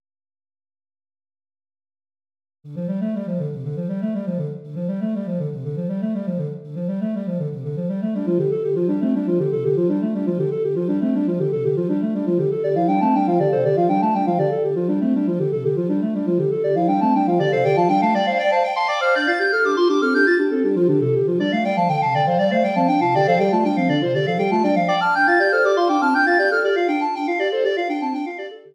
S A A T B B